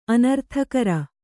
♪ anarthakara